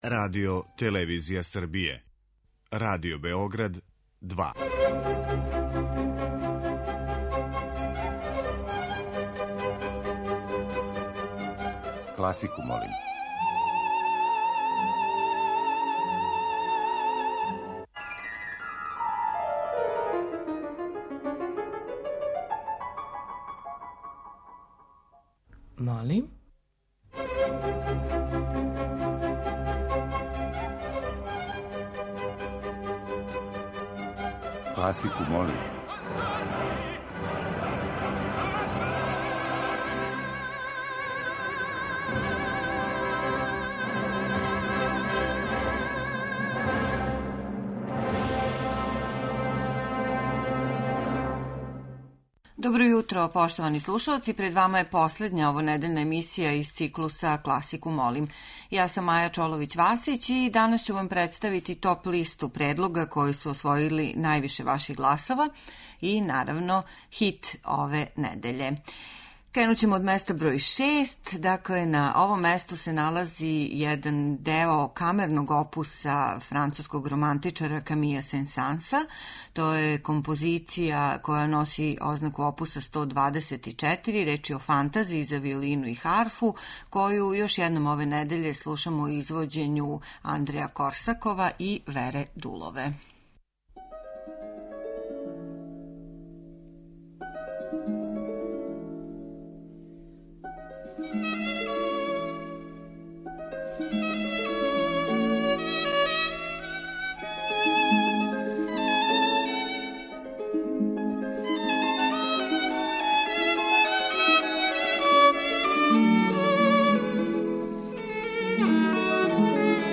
Избор за недељну топ-листу класичне музике Радио Београда